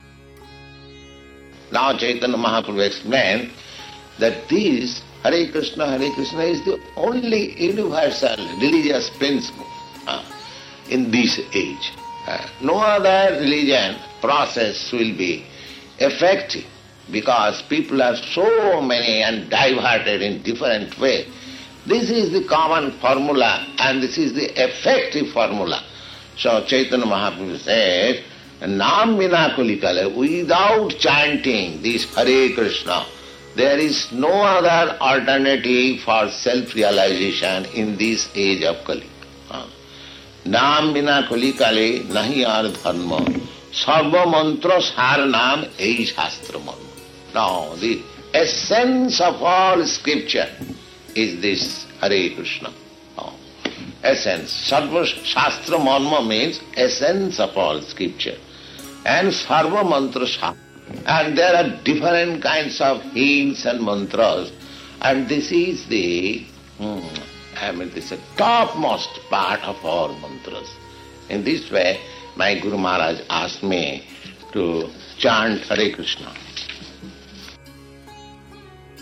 (750811 – Lecture Arrival – Paris)